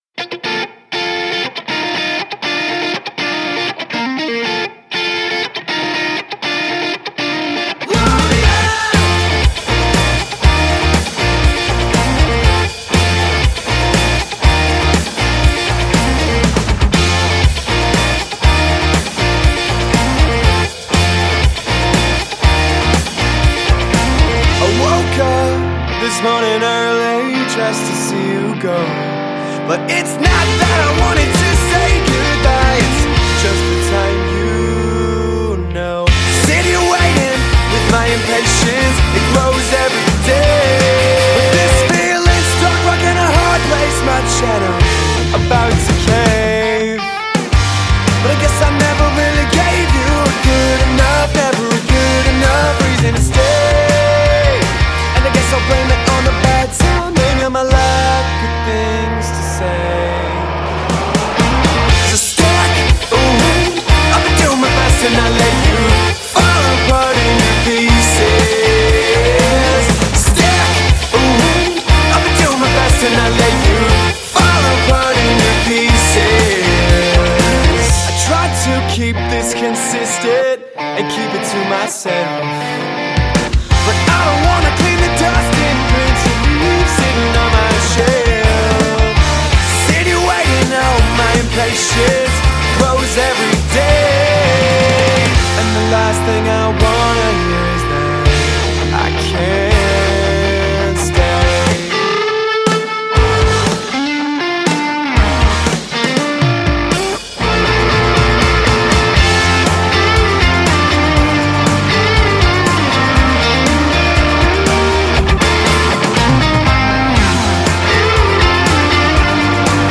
By combining a mix of his punk rock roots with a […]